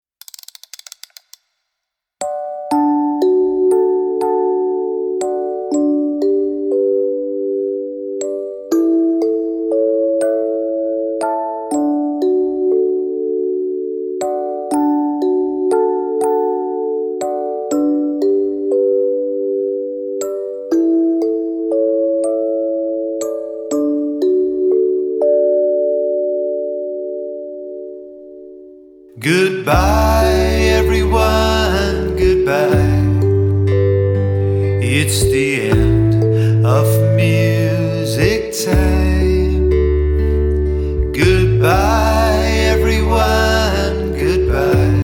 (farewell song)